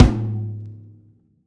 drum-hitfinish.ogg